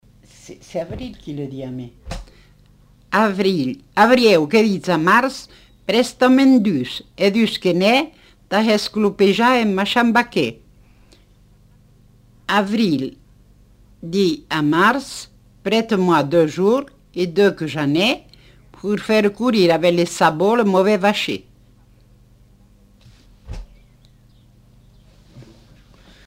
Lieu : Montauban-de-Luchon
Genre : forme brève
Effectif : 1
Type de voix : voix de femme
Production du son : récité
Classification : proverbe-dicton